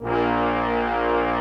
BRASS 3A#2.wav